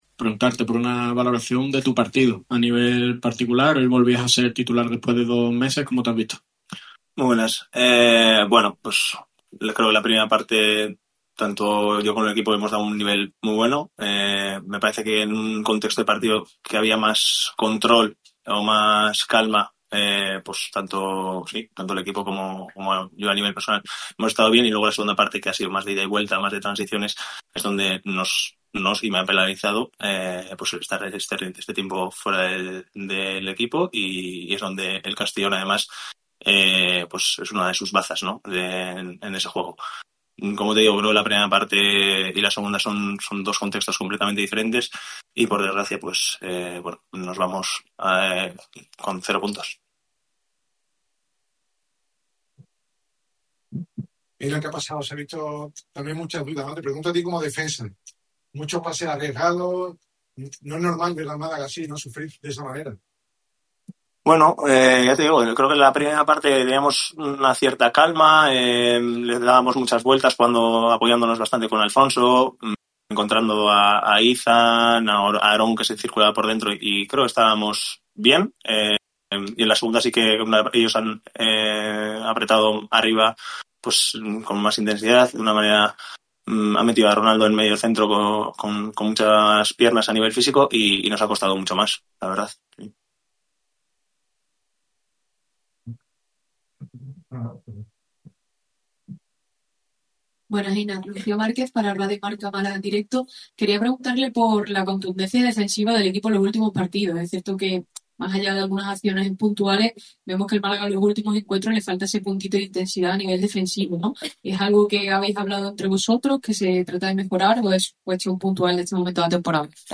Aun así, tras el encuentro, compareció ante los medios de comunicación para valorar la derrota desde el plano individual y colectivo.
Declaraciones íntegras